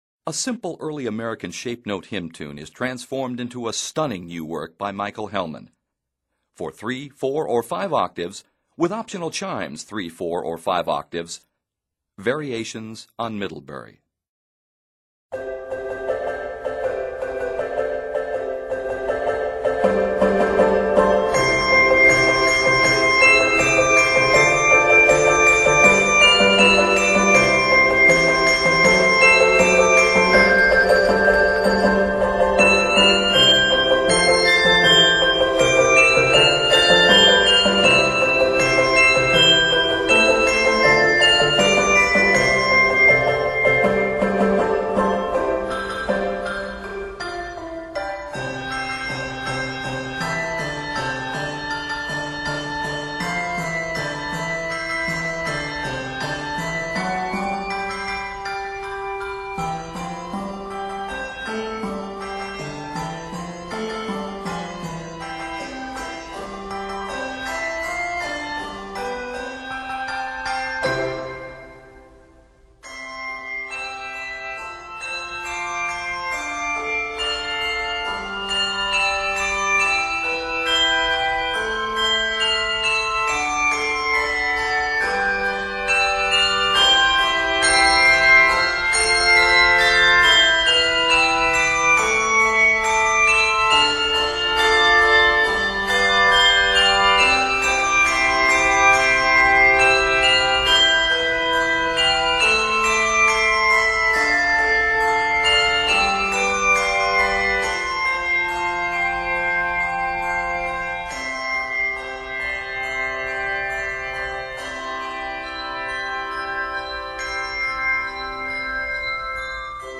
is scored in C Major and c minor